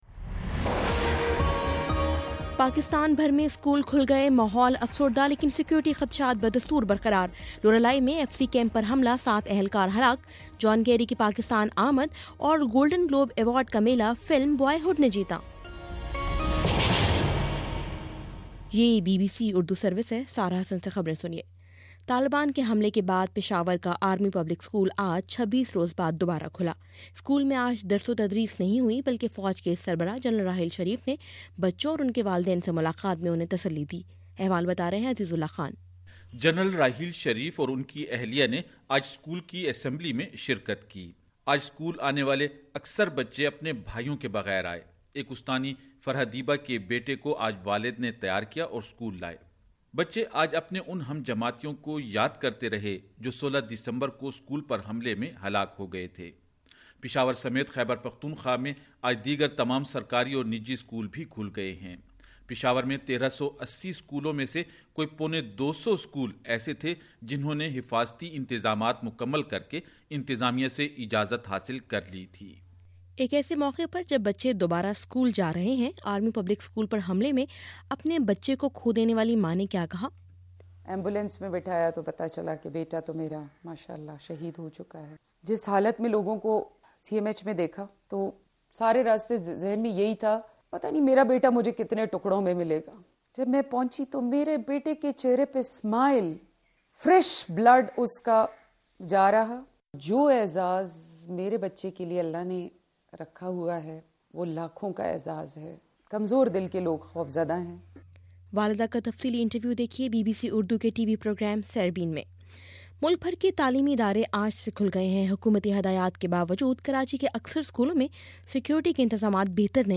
جنوری 12: شام چھ بجے کا نیوز بُلیٹن